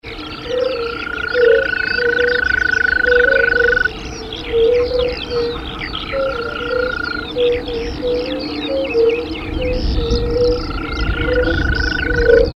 Ruf Wechselkröte
Zur Paarungszeit geben die Männchen nachts trillernde, bis zu zehn Sekunden andauernde Rufe von sich, die wie „ürrr“ klingen und dem Zirpen der Maulwurfsgrille ähneln.
Wechselkroete.mp3